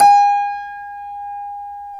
Index of /90_sSampleCDs/Club-50 - Foundations Roland/PNO_xTack Piano/PNO_xTack Pno 1M